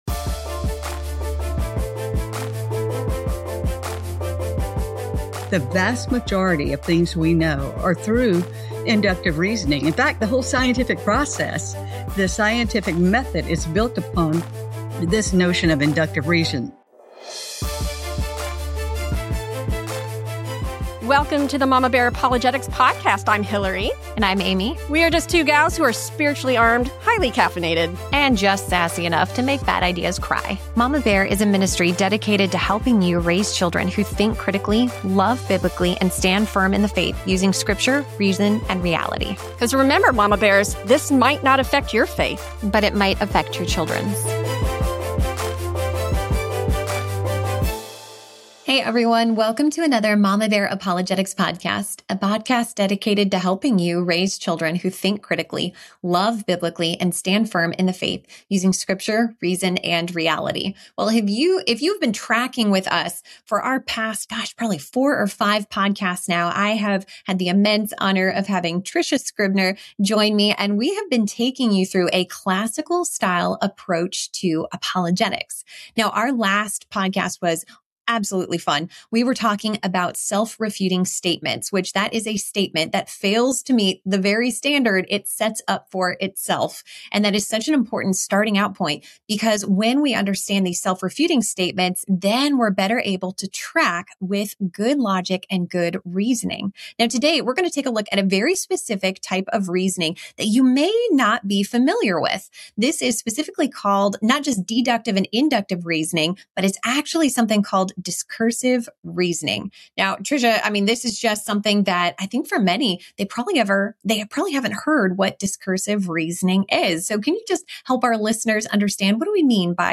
This conversation is a mental workout (in the best way possible!). The mamas unpack the difference between deductive reasoning and inductive reasoning. They emphasize that Christianity does not require blind faith—it calls us to love God with our minds and think well.